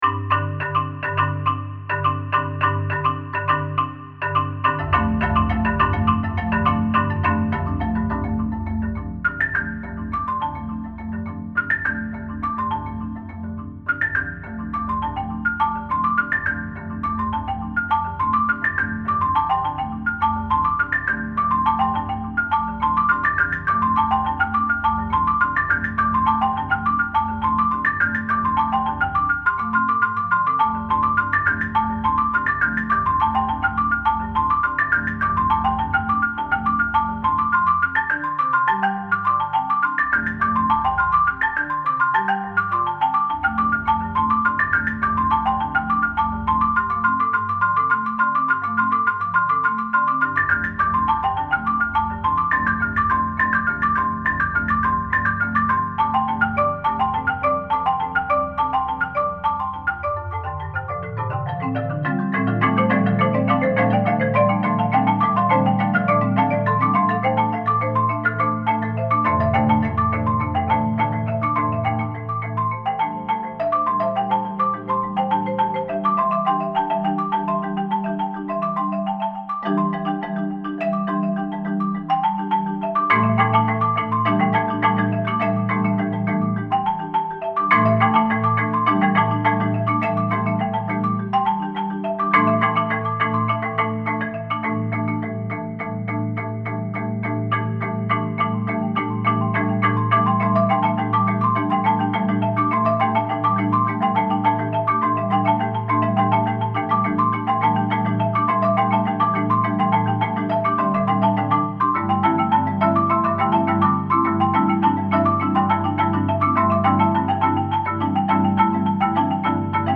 For eight hands on two marimbas
Voicing: Mallet Quartet